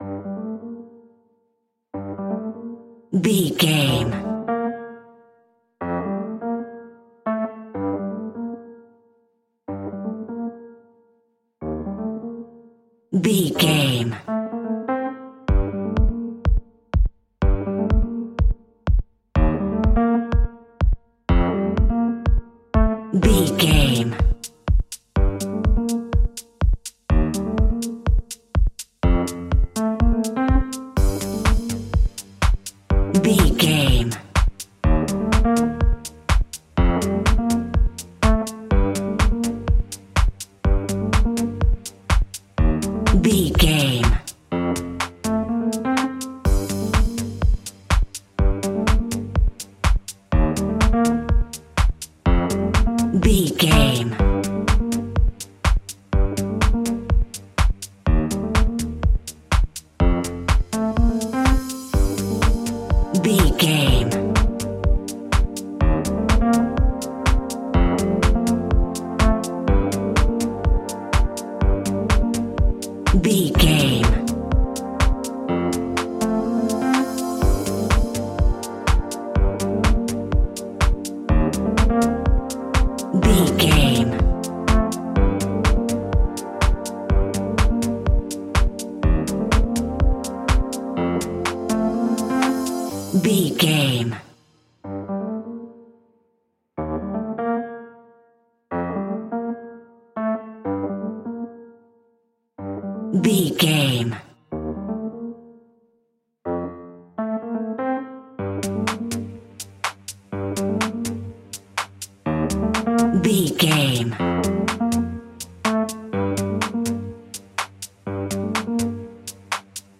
Aeolian/Minor
groovy
synthesiser
drum machine
electric piano
funky house
deep house
nu disco
upbeat
funky guitar
synth bass